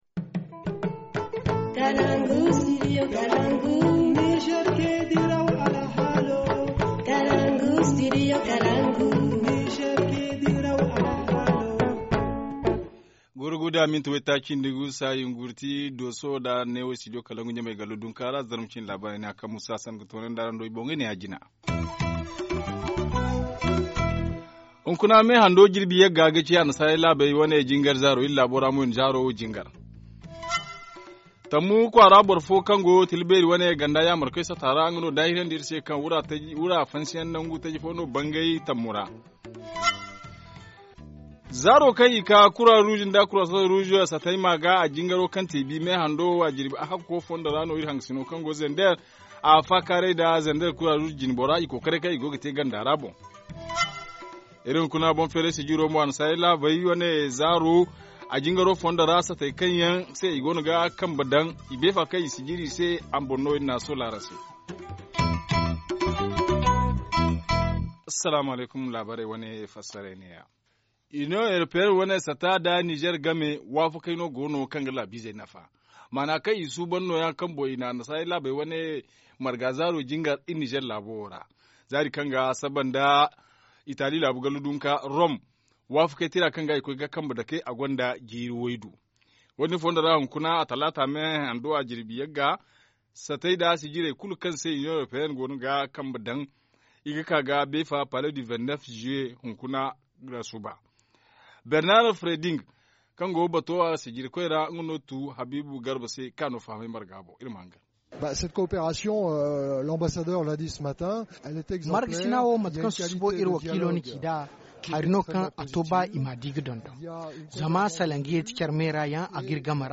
2. Un nouveau site aurifère a été découvert à Tamou région de Tillabéry depuis le 13 Février dernier. Ce site est exploité de manière artisanale nous témoigne un ressortissant de TAMOU.
Journal en français